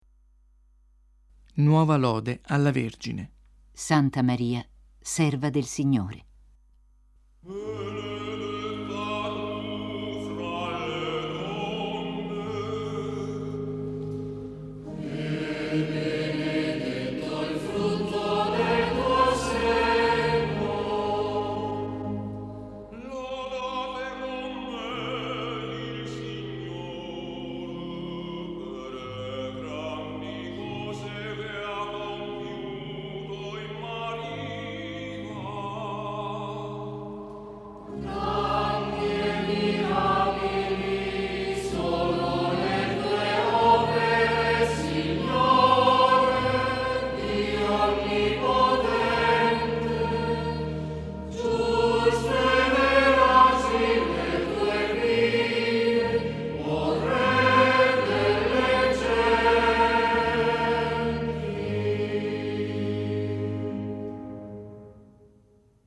Coro: